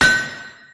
hammerhithard.ogg